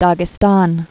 (dägĭ-stän, dăgĭ-stăn)